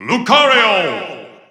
The announcer saying Lucario's name in English and Japanese releases of Super Smash Bros. 4 and Super Smash Bros. Ultimate.
Lucario_English_Announcer_SSB4-SSBU.wav